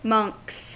Uttal
Uttal US Okänd accent: IPA : /mʌŋks/ Ordet hittades på dessa språk: engelska Ingen översättning hittades i den valda målspråket.